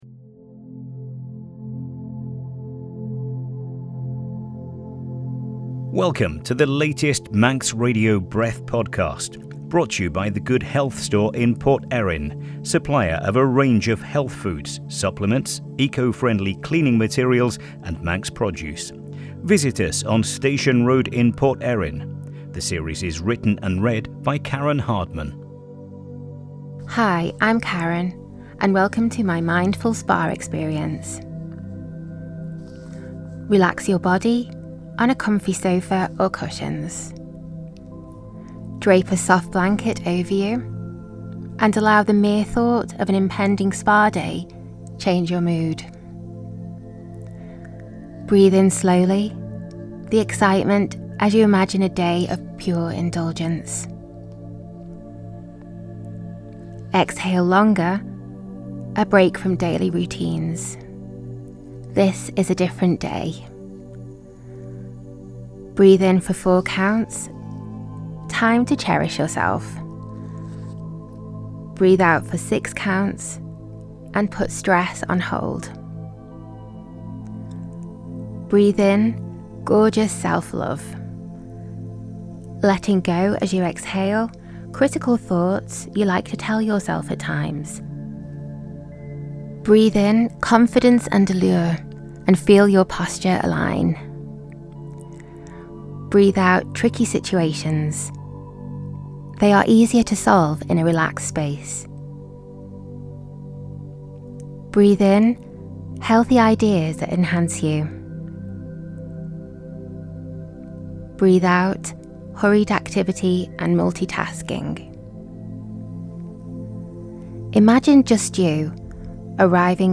Step into a moment of pure calm with Mindful Spa Experience - a soothing escape designed to help you unwind without ever leaving home.
Settle in, wrap yourself in a blanket, and allow your body to fully relax as you’re gently guided through a blissful, spa-inspired journey.